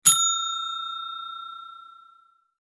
gift_tone.mp3